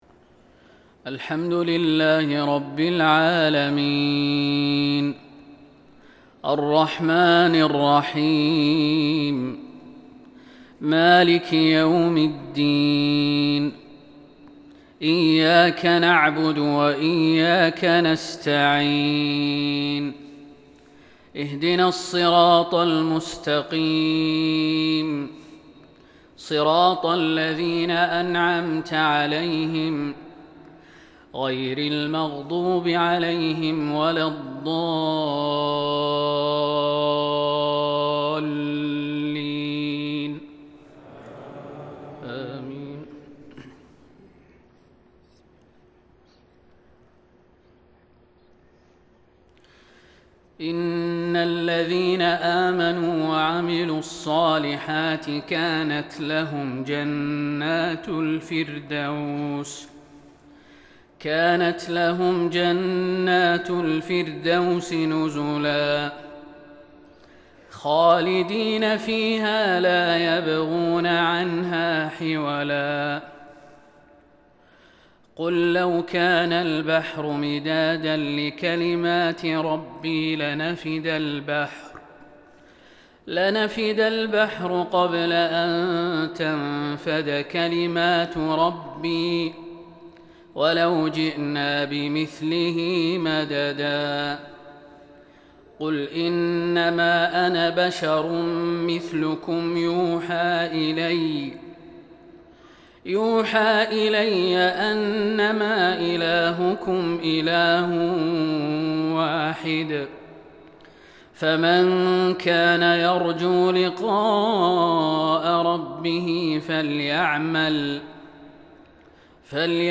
صلاة المغرب من سورتي الكهف ولقمان | الخميس ١٢ ربيع الأول ١٤٤٢ه‍ـ | maghrib prayer from Surat Al-Kahf and surat Luqman | 29/10/2020 > 1442 🕌 > الفروض - تلاوات الحرمين